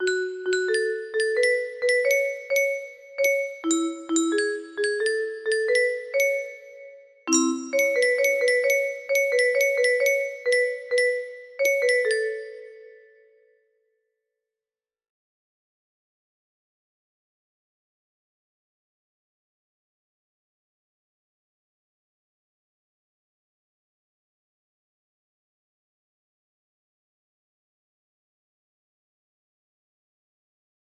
45-48 music box melody